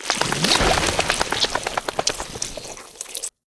shake.ogg